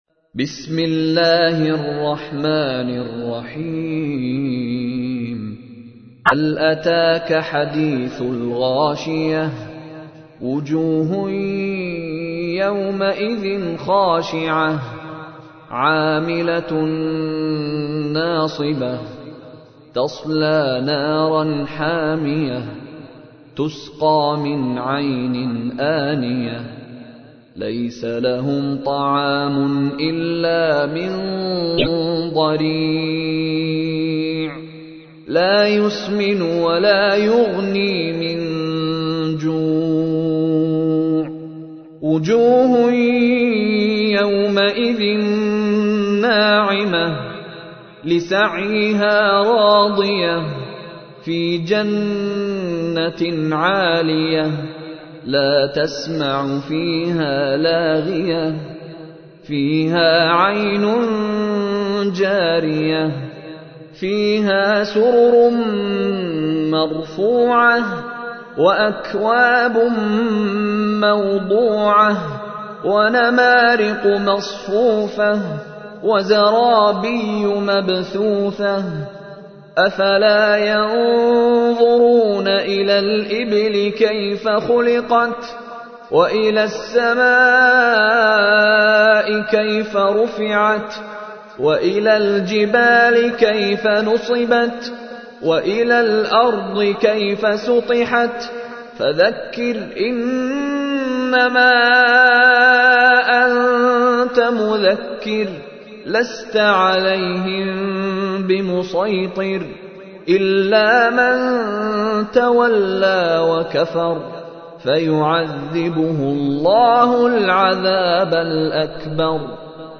تحميل : 88. سورة الغاشية / القارئ مشاري راشد العفاسي / القرآن الكريم / موقع يا حسين